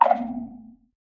1.21.4 / assets / minecraft / sounds / mob / warden / tendril_clicks_6.ogg
tendril_clicks_6.ogg